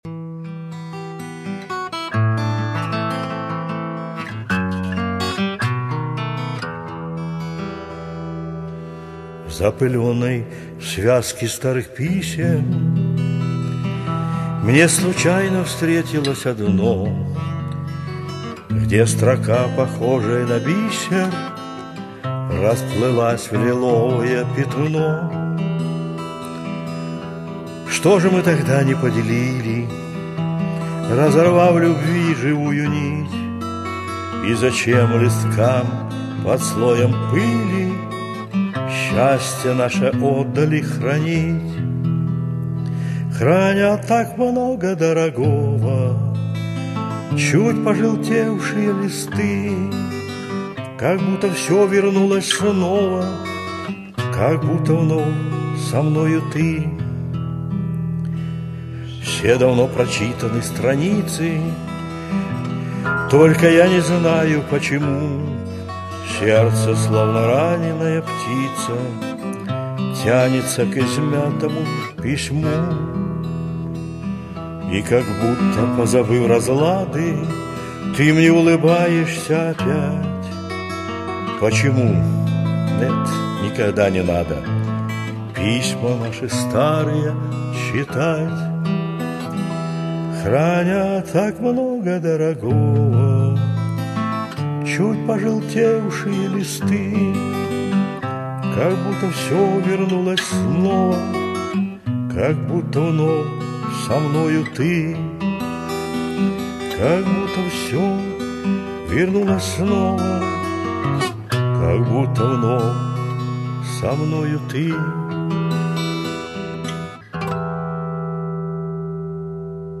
романс
но романс спет душевно....